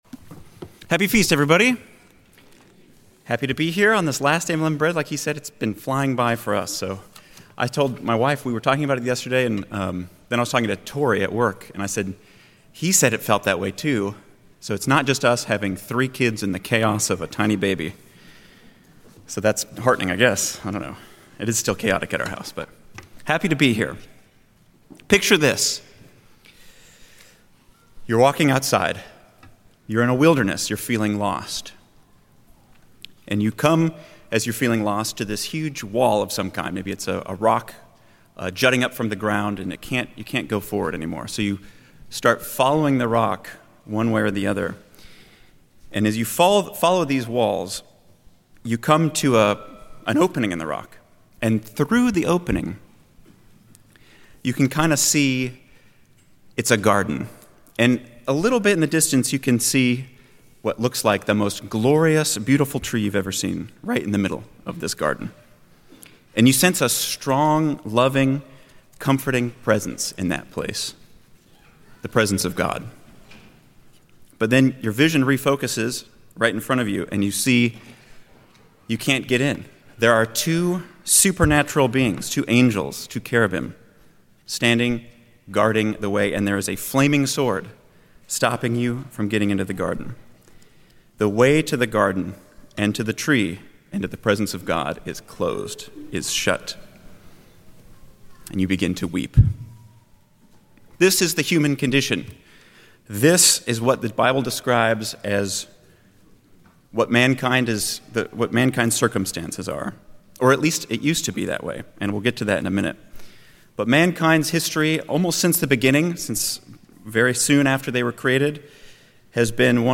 This sermonette walks through a powerful biblical theme—from the Garden of Eden to the empty tomb—showing how humanity’s access to God was once closed, but has now been reopened through Jesus Christ. By connecting the symbols of the tree of life, the ark of the covenant, and Christ’s resurrection, it highlights the incredible truth that the way to eternal life is no longer shut.
Given in Cincinnati East, OH